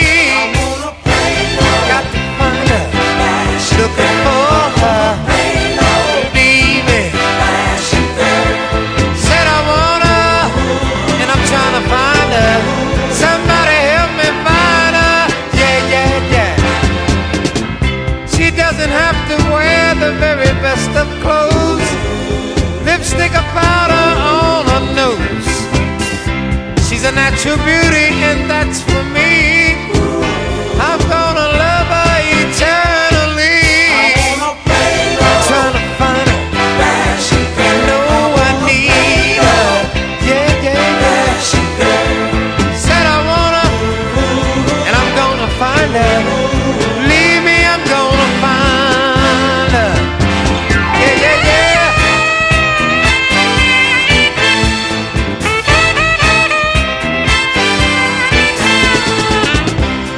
ROCK / 70'S / FUNKY ROCK / PSYCHEDELIC ROCK
サイケデリックなモッド・ファンキー・ロック